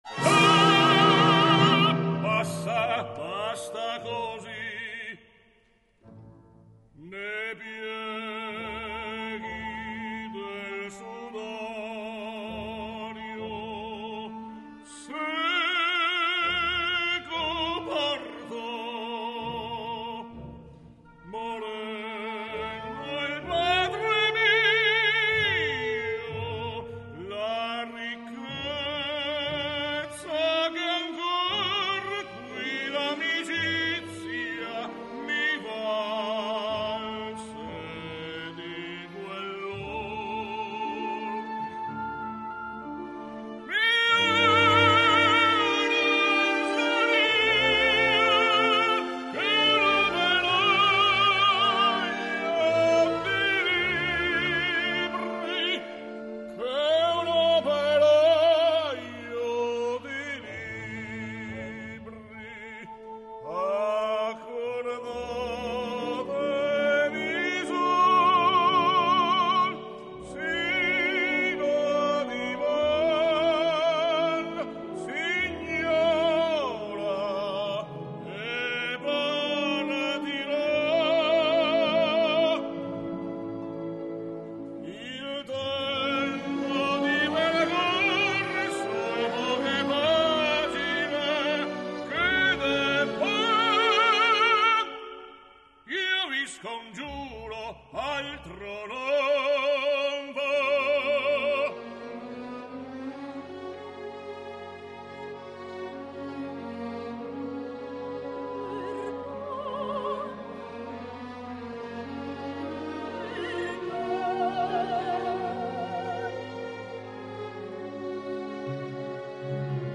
(Leider ist der Tenor mit seiner Rolle des Chatterton völlig überfordert und es ist nicht immer ein Hörgenuss!
Tommaso Chatterton [Tenor]
Jenny Clark [Sopran]